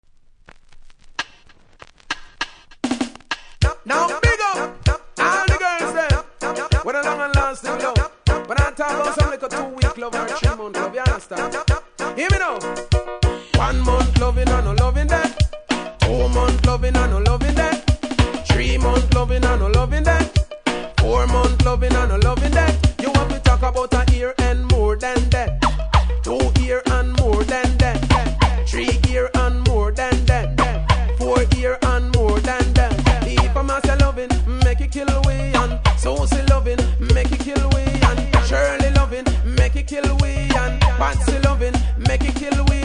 REGGAE 80'S